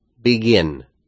Ces verbes ont un schéma accentuel oxyton (/01/).
begin /01/ /bi.’gɪn/ /bi.’gɪn/